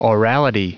Prononciation du mot orality en anglais (fichier audio)
Prononciation du mot : orality